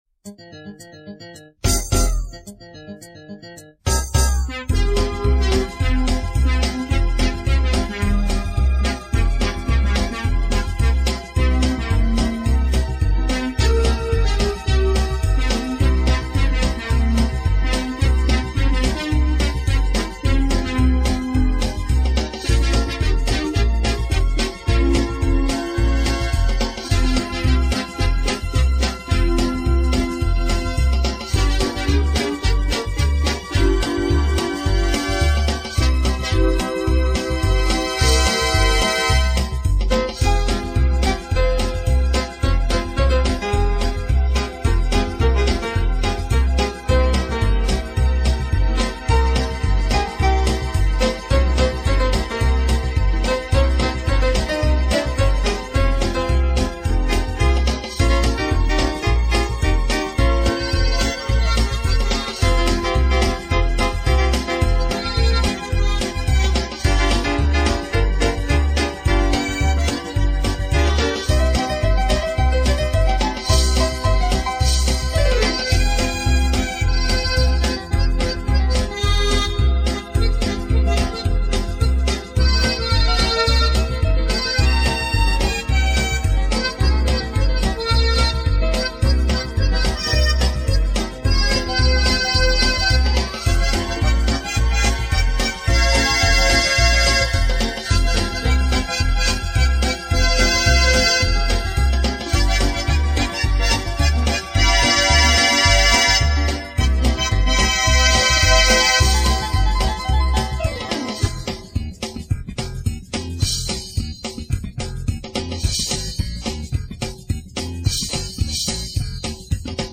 инструментальная пьеса